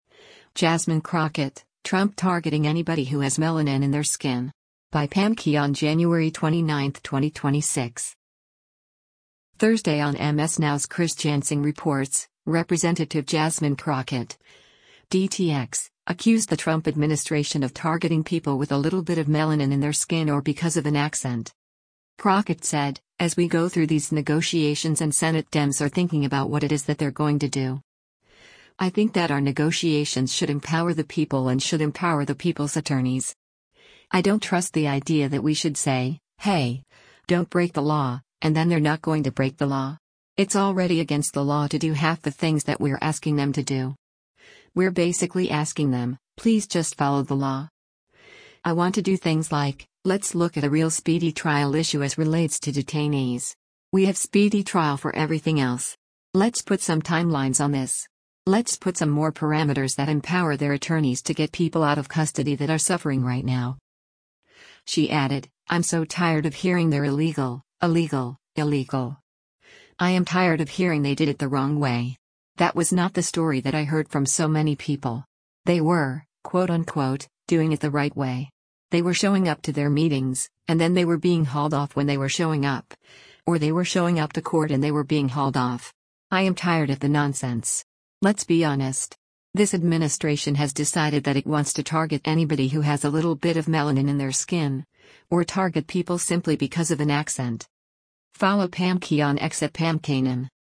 Thursday on MS NOW’s “Chris Jansing Reports,” Rep. Jasmine Crockett (D-TX) accused the Trump administration of targeting people with “a little bit of melanin in their skin” or “because of an accent.”